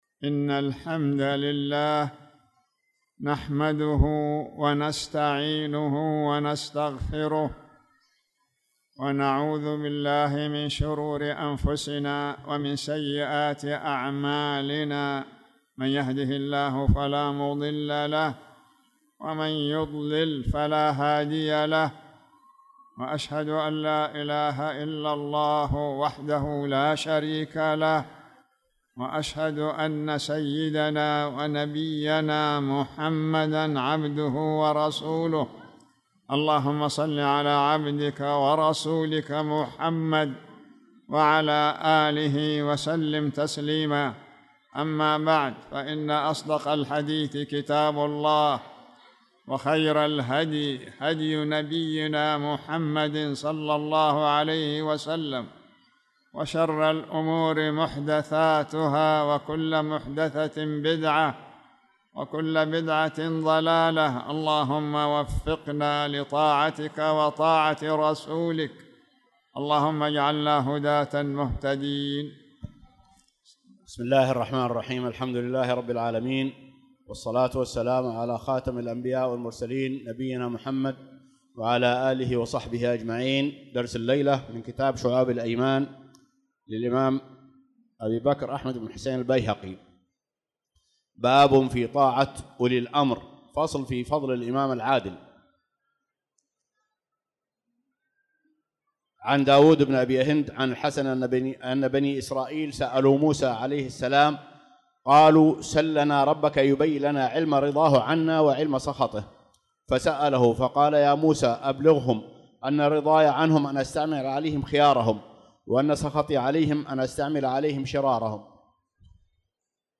تاريخ النشر ٢٨ شوال ١٤٣٧ هـ المكان: المسجد الحرام الشيخ